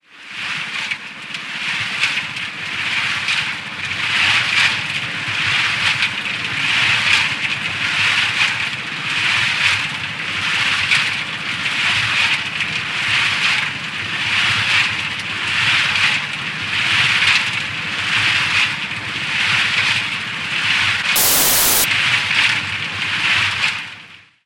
Звук перемешивания компонентов в миксере